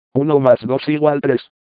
Spanish Text to Speech Voices - MWS Reader
Lernout & Hauspie® TTS3000 TTS engine – Spanish